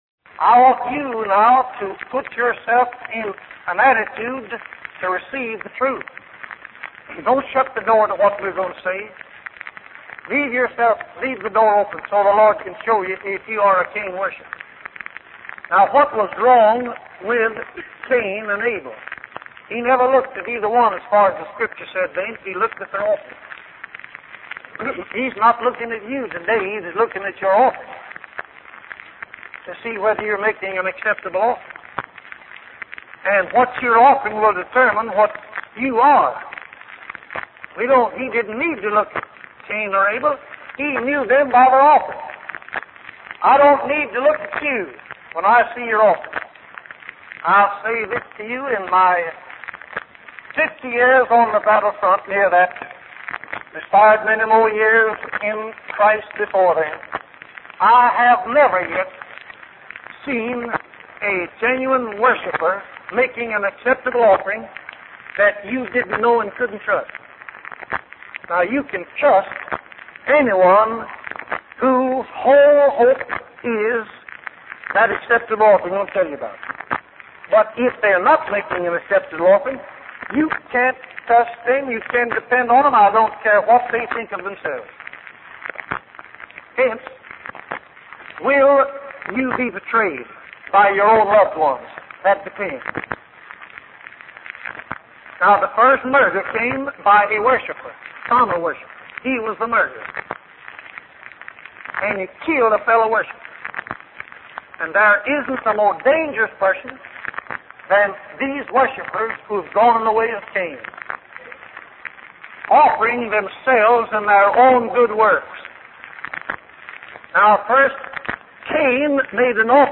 In this sermon, the preacher emphasizes the importance of being either hot or cold in one's faith. He asks the congregation if they want to know if they are acceptable in heaven and urges them to lift their hands if they do.